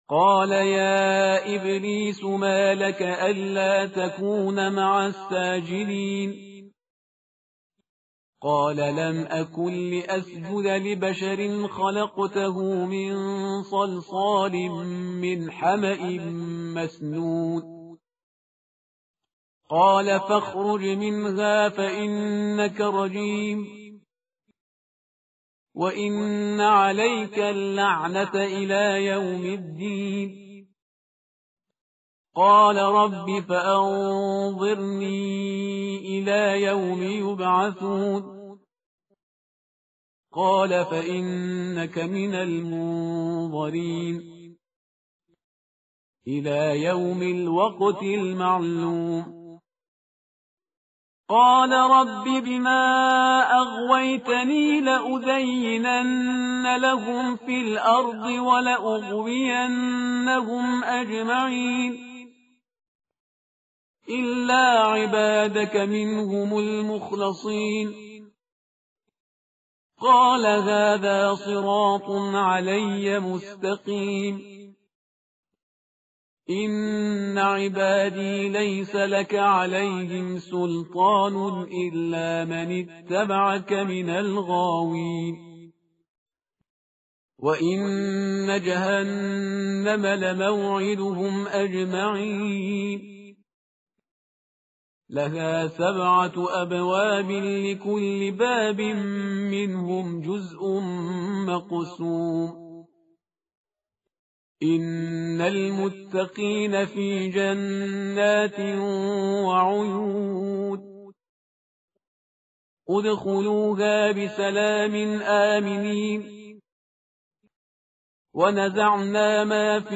tartil_parhizgar_page_264.mp3